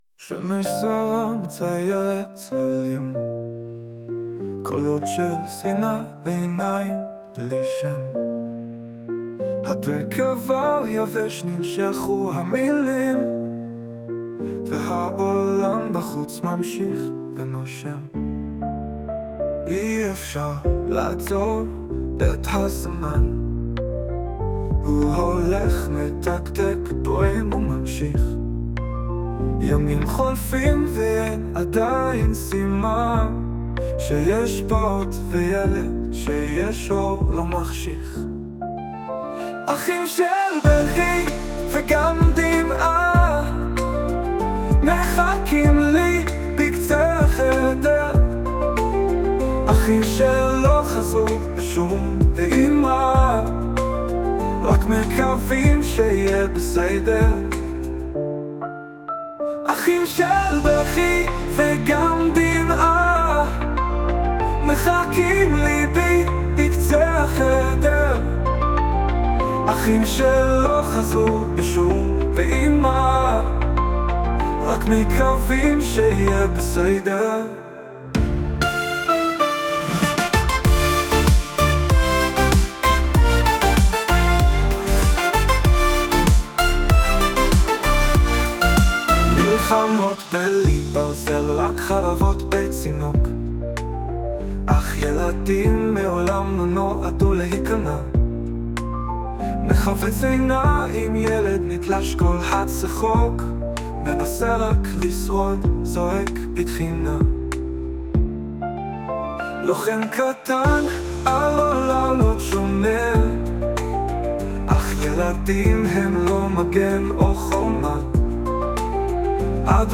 ישנם חסרונות רבים היכולים להיגרם ע"י שירת רובוט.
לפעמים השיר די טוב, הלחן טוב והעיבוד סוף סוף מהמם. אבל הוא לגמרי! לא! מתאים! לאווירה של השיר!